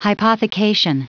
Prononciation du mot hypothecation en anglais (fichier audio)
Prononciation du mot : hypothecation